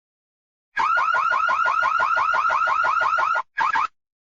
Car Alarm Sound Effect Free Download
Car Alarm